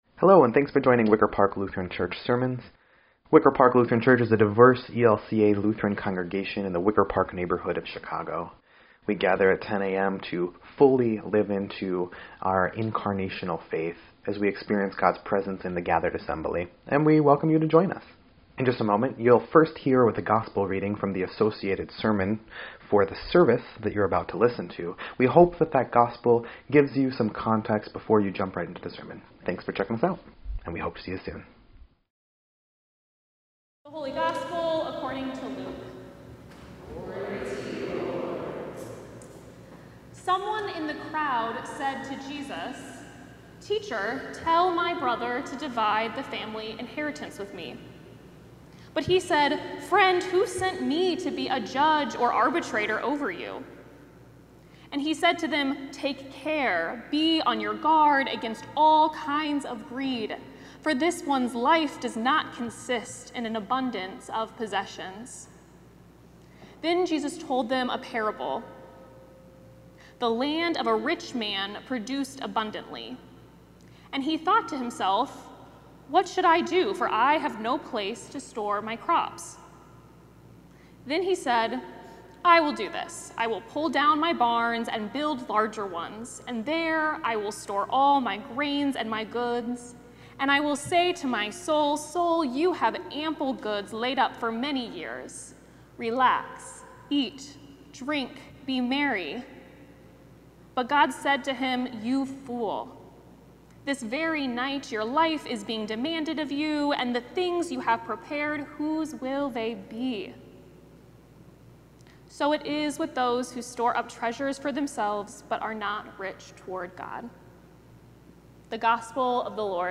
Ninth Sunday after Pentecost
8.3.25-Sermon_EDIT.mp3